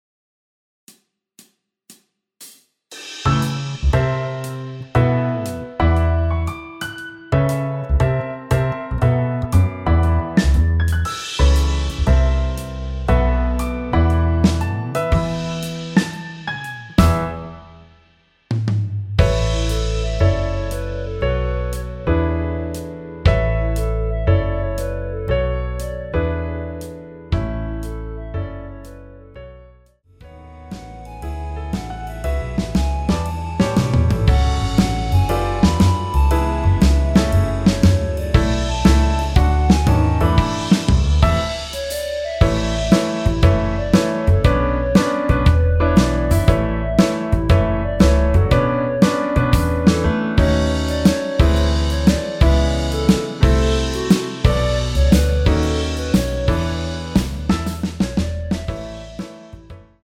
원키에서(+2)올린 멜로디 포함된 MR입니다.(미리듣기 참조)
앞부분30초, 뒷부분30초씩 편집해서 올려 드리고 있습니다.
중간에 음이 끈어지고 다시 나오는 이유는